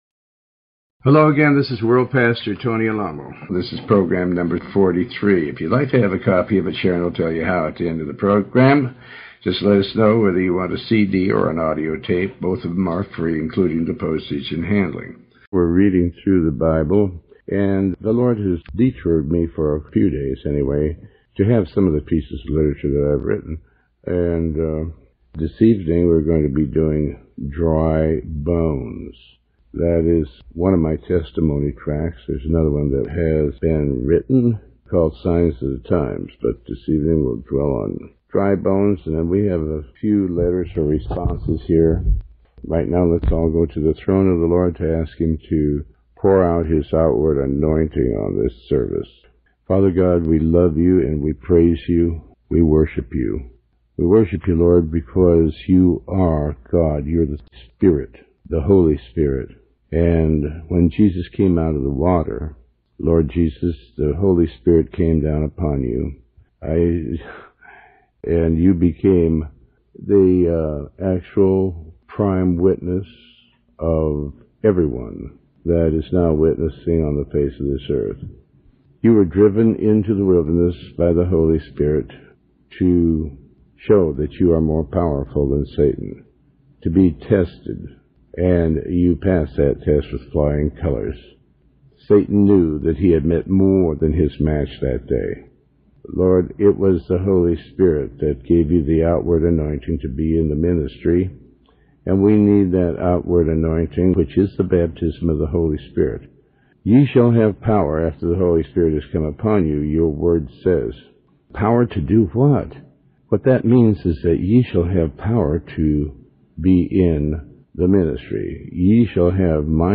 Show Headline Tony Alamo Show Sub Headline Pastor Tony Alamo Dry Bones Tony Alamo with Tony Alamo World Wide Ministries Pastor Tony Alamo Dry Bones In this program originally aired in 2005, Pastor Alamo has read, and comments on a piece of literature titled, "Dry Bones." This is a brief description of his salvation experience and testimony.